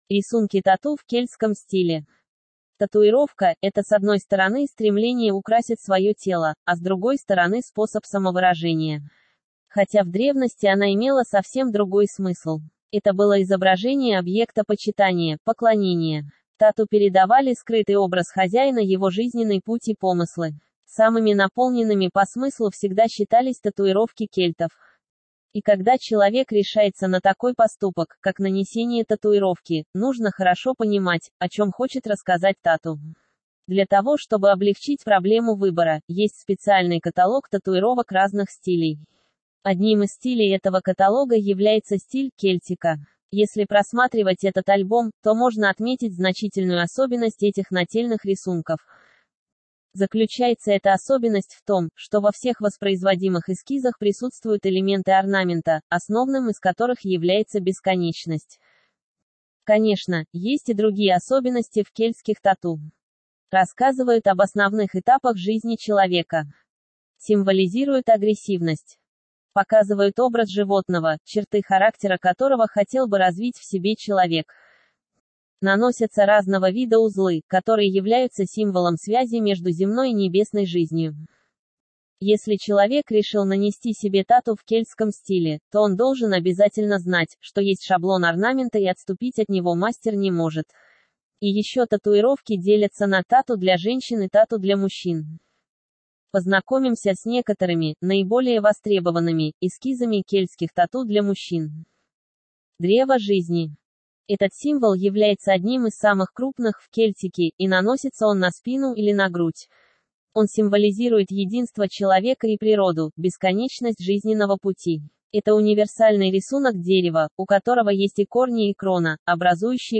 Рисунки-тату-в-кельтском-стиле-аудио-версия-статьи-для-сайта-tatufoto.com_.mp3